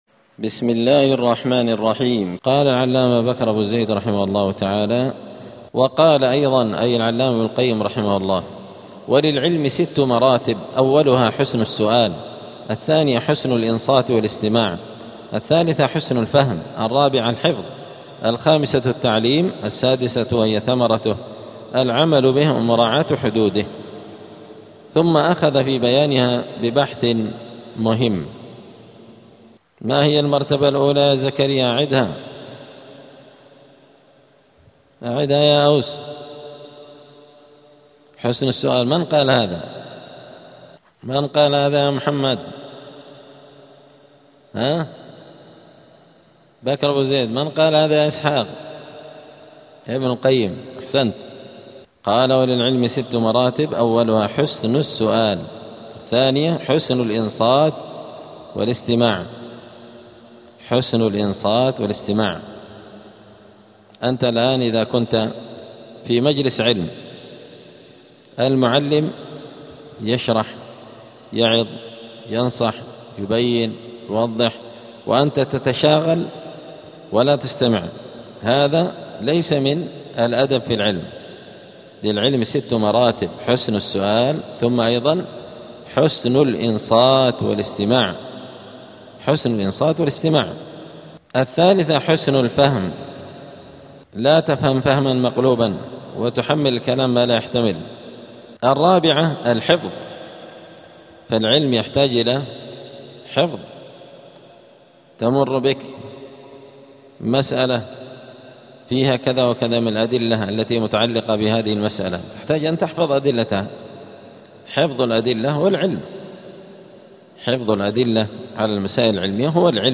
*الدرس الرابع والثمانون (84) فصل آداب الطالب في حياته العلمية {الحفظ والتعليم}.*